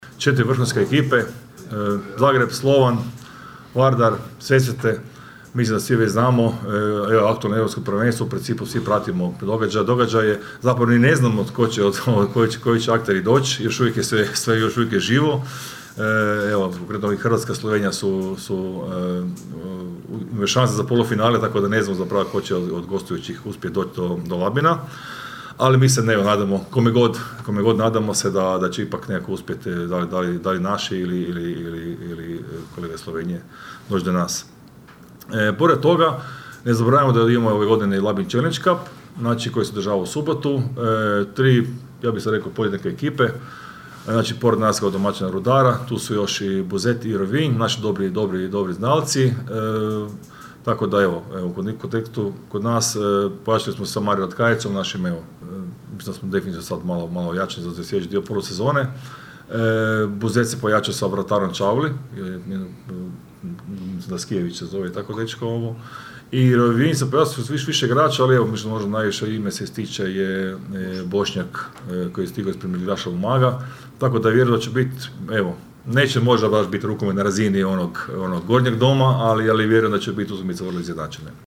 na konferenciji za novinare